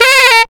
07-Saxual 4.wav